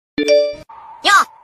Kategori: Nada dering
Keterangan: Ini adalah notif Yo Monkey D Luffy Anime favorit banyak orang.